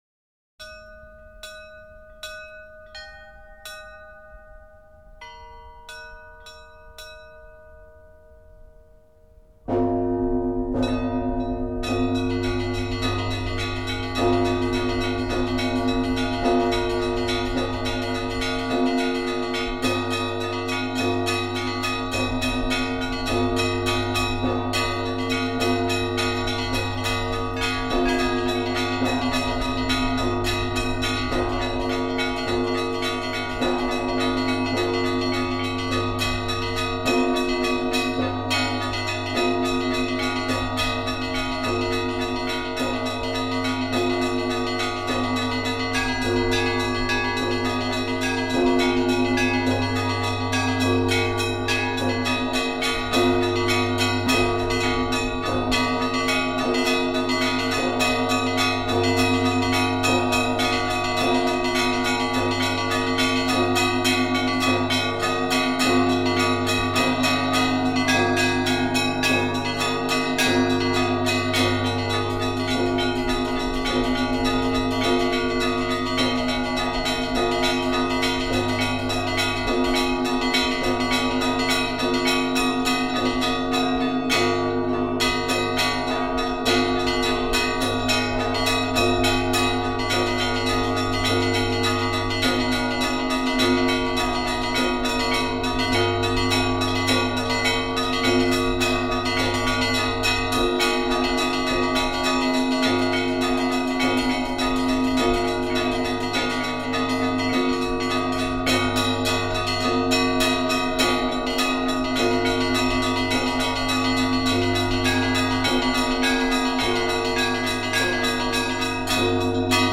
15_Prazdnichnyj_Trezvon.mp3